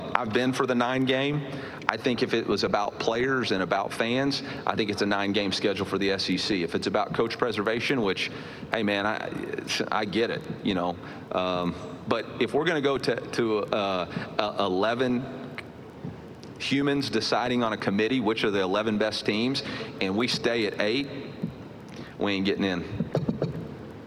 Mizzou head coach Eli Drinkwitz at SEC Media Day